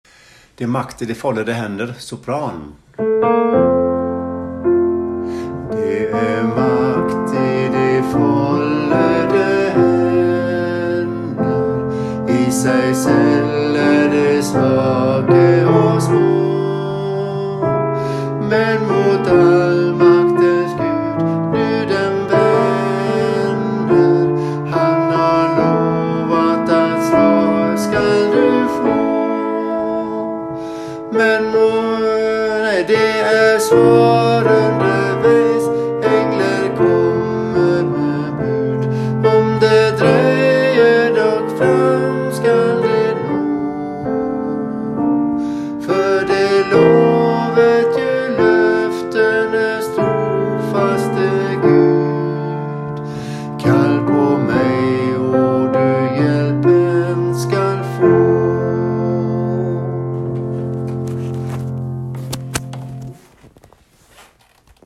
Det är makt i de follede hender sop
det er makt_sopran.mp3